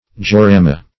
georama - definition of georama - synonyms, pronunciation, spelling from Free Dictionary
Search Result for " georama" : The Collaborative International Dictionary of English v.0.48: Georama \Ge`o*ra"ma\, n. [Gr. ge`a, gh^, the earth + ? sight, view, ? to see, view: cf. F. g['e]orama.]